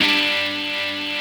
ChordG5.wav